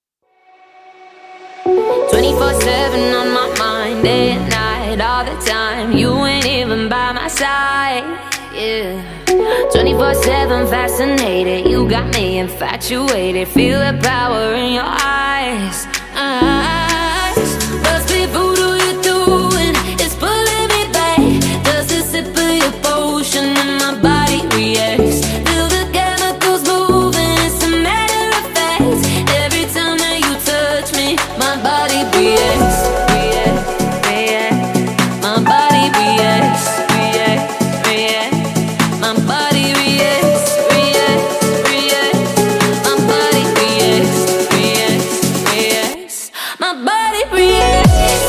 • Качество: 320, Stereo
remix
клавишные
house